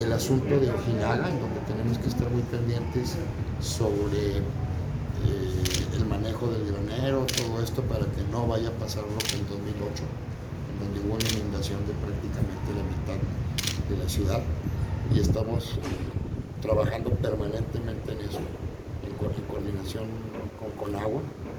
Audios. Secretario General de Gobierno, César Jáuregui Moreno.